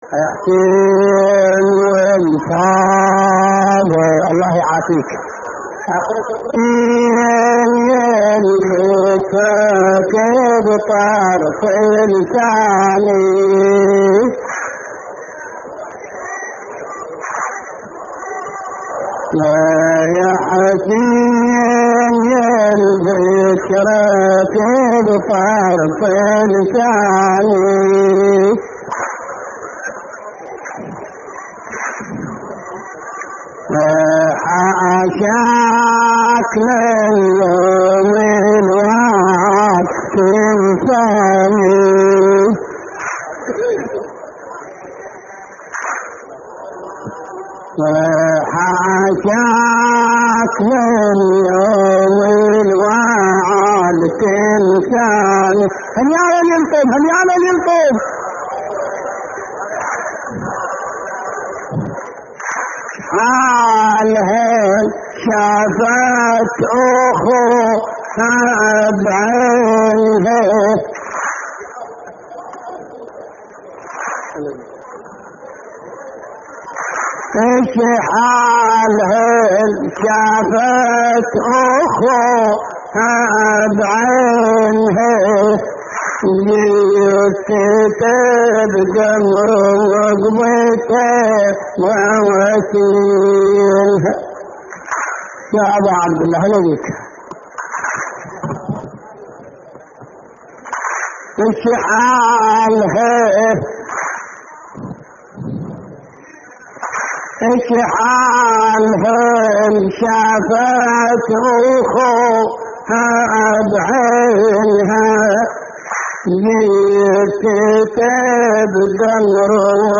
تحميل : شحالها اللي شافت أخوها بعينها / الرادود حمزة الصغير / اللطميات الحسينية / موقع يا حسين